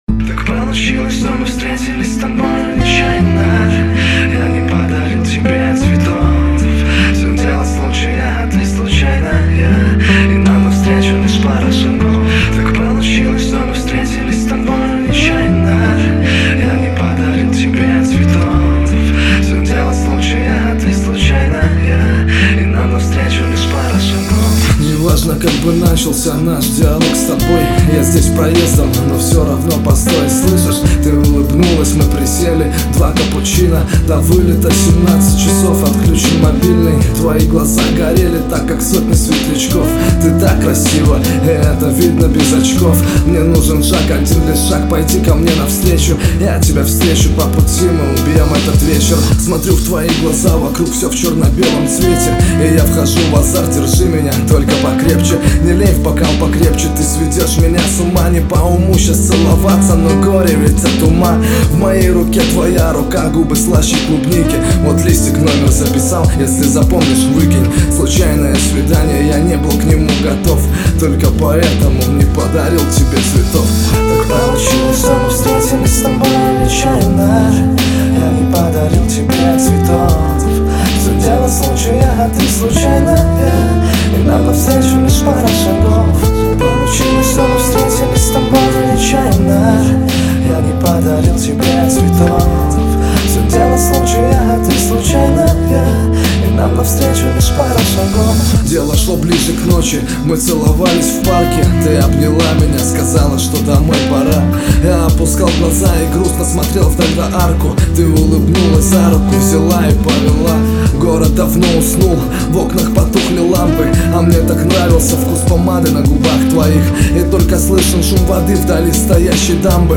Категория: Рэп